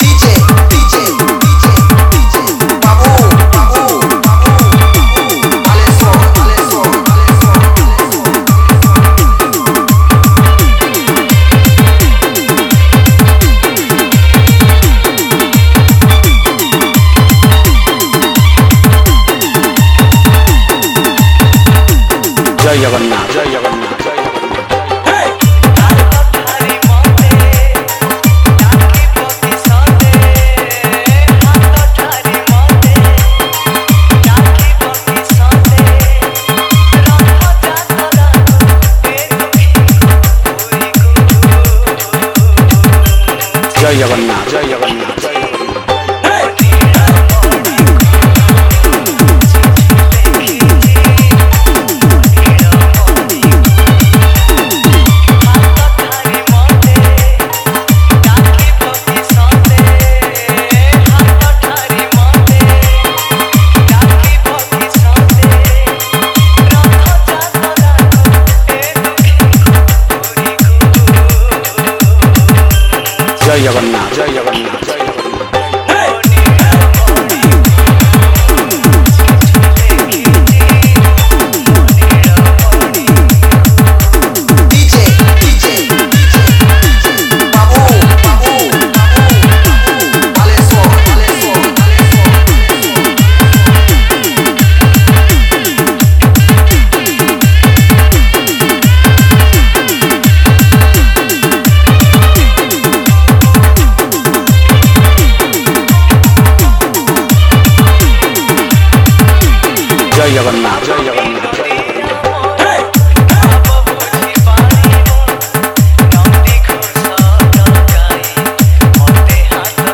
Category:  Odia Bhajan Dj 2020